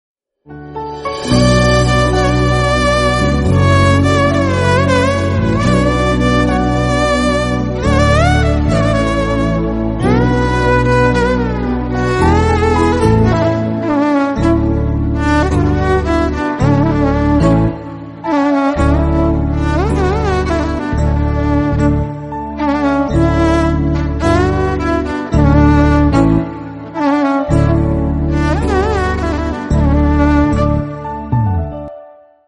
romantic duet
The Iconic Violin Rendition